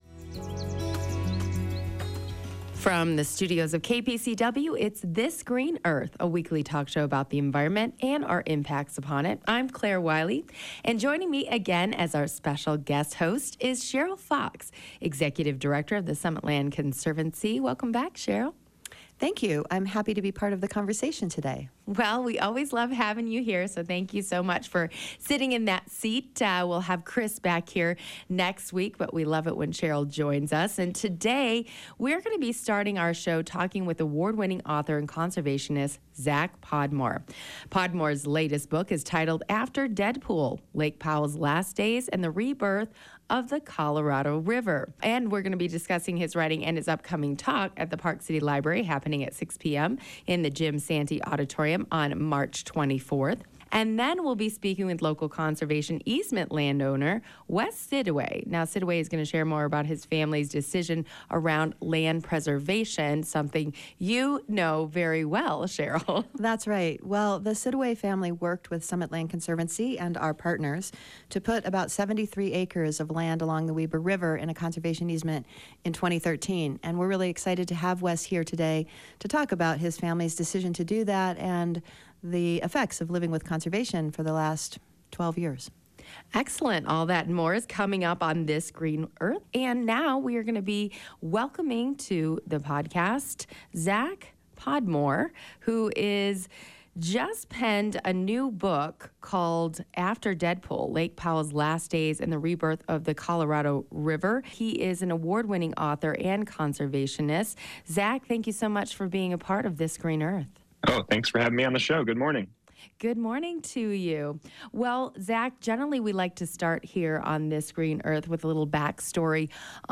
1 In celebration of Earth Day: a conversation on the deep roots of regenerative farming 51:00